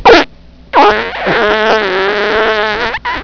fart6.wav